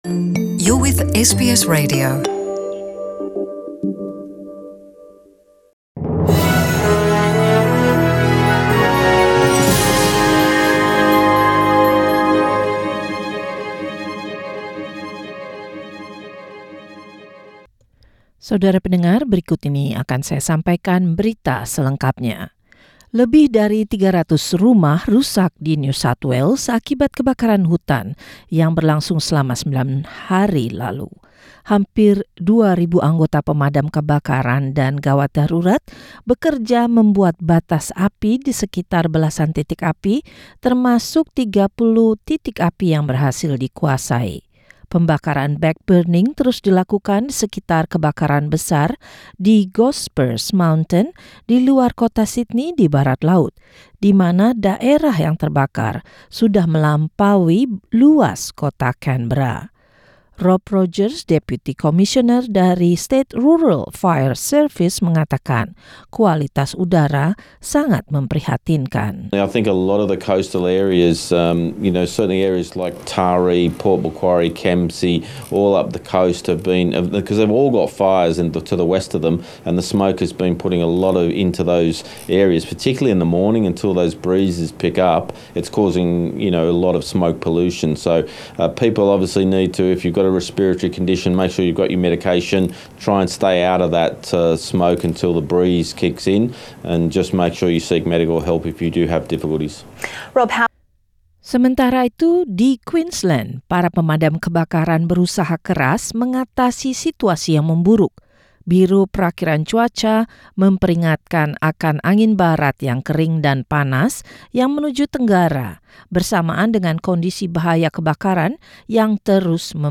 Warta Berita Radio SBS dalam Bahasa Indonesia 17/11/2019